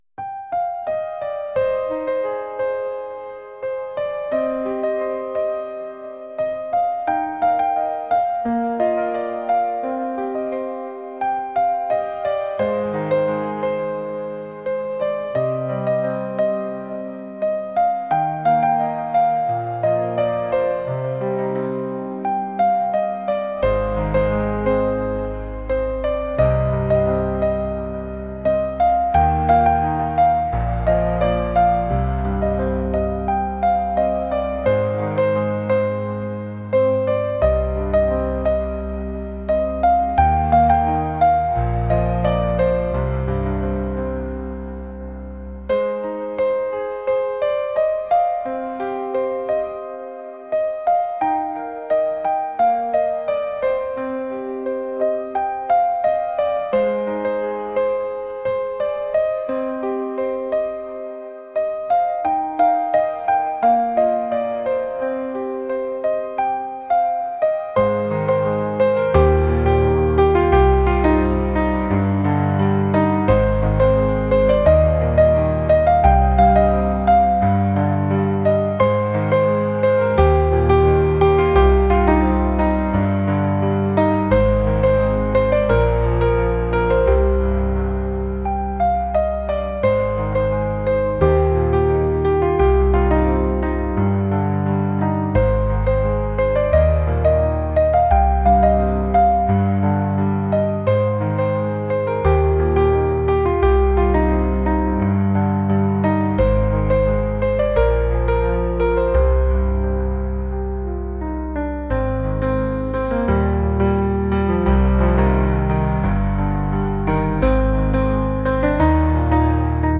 electronic | pop | ambient